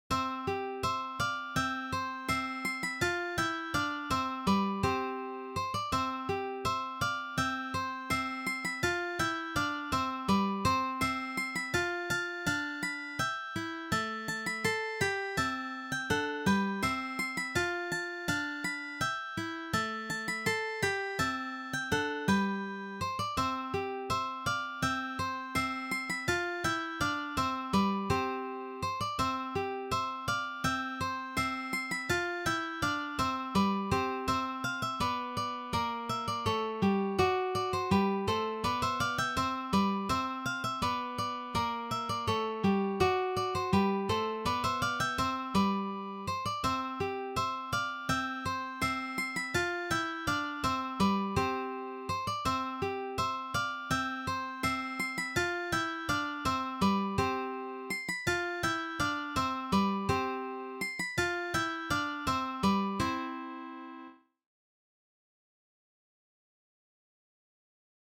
This Baroque selection is arranged for guitar trio.